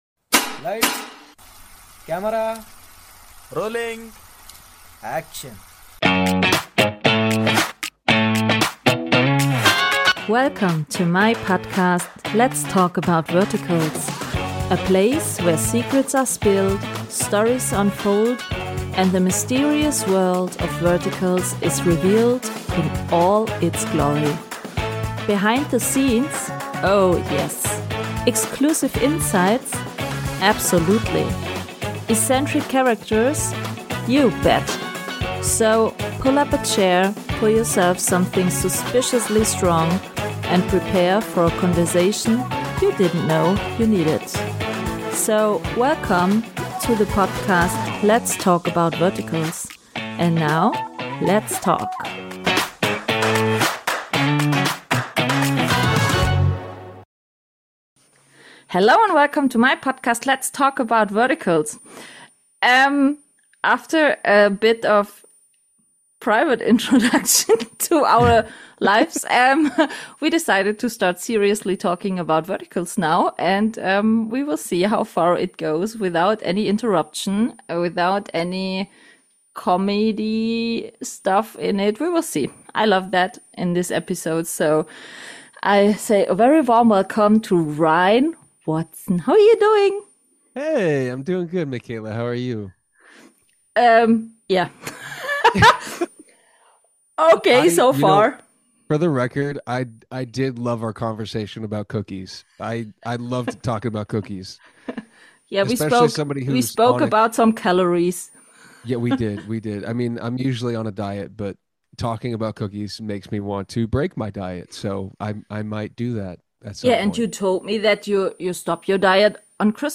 Humor, Fun Facts & Real Talk from the Vertical World This episode is packed with laughs, behind-the-scenes fun facts, and also some serious conversations from the Vertical universe.